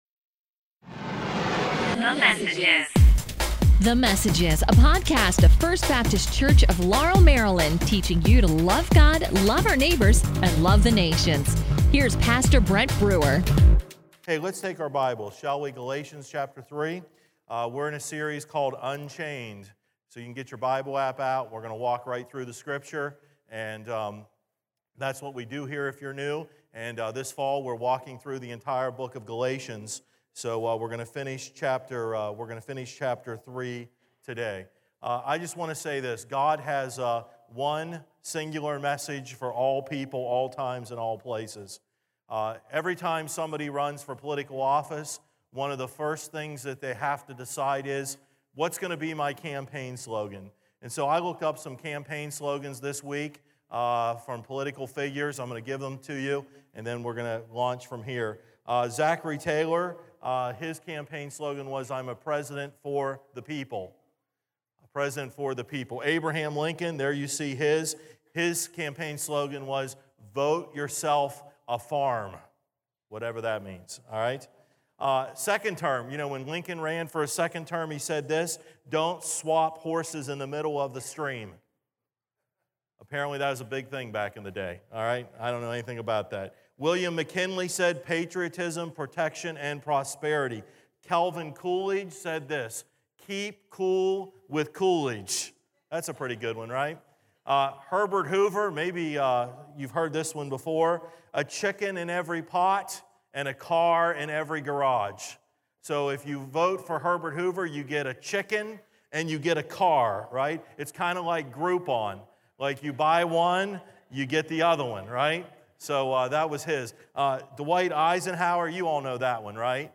A message from the series "God Rules Over All ."